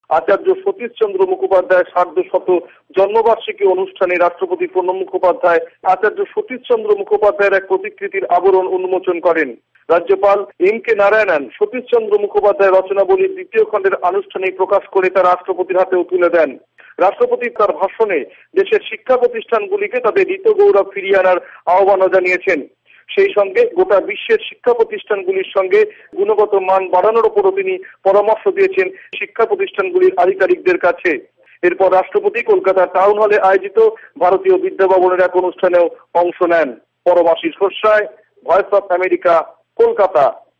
ভয়েস অব আমেরিকার কোলকাতা সংবাদদাতাদের রিপোর্ট